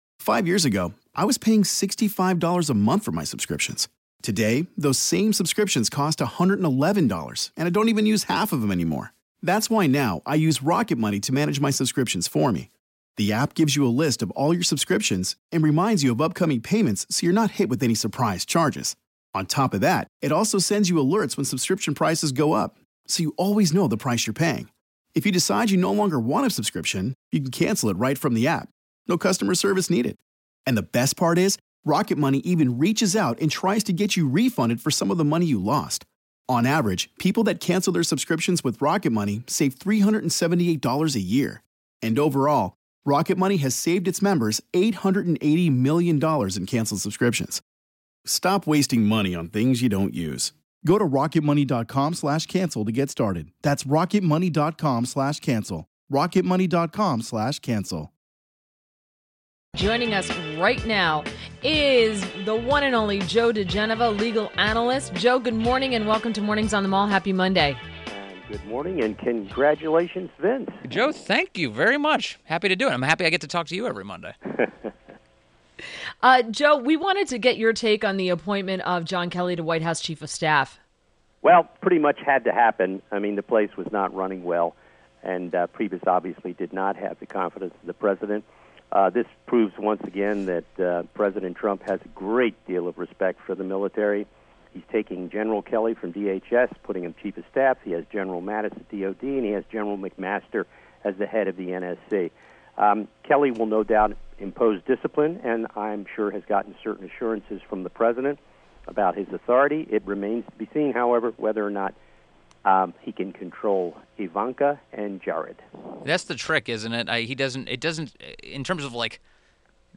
WMAL Interview - JOE DIGENOVA 07.31.17
INTERVIEW -- JOE DIGENOVA - legal analyst and former U.S. Attorney to the District of Columbia